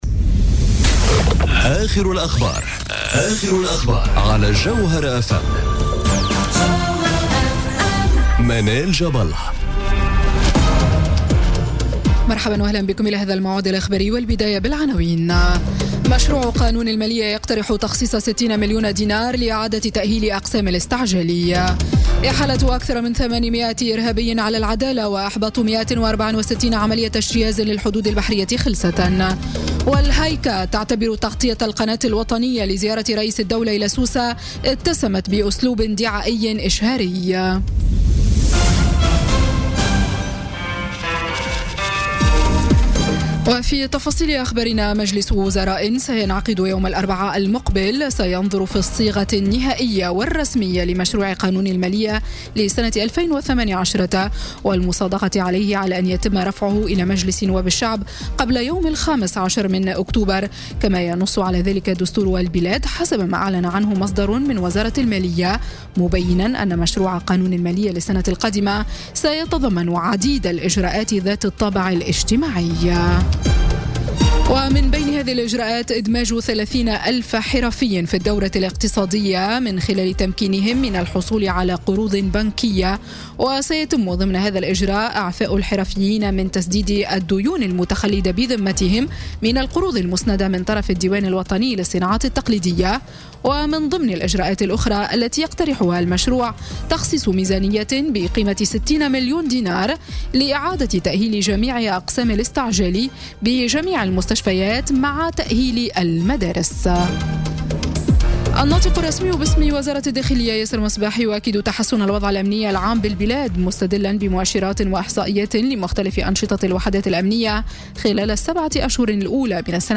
نشرة أخبار السابعة مساء ليوم الجمعة 6 أكتوبر 2017